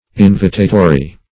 Invitatory \In*vi"ta*to*ry\, a. [L. invitatorius: cf. F.